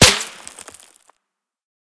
bulletFlyBy_1.ogg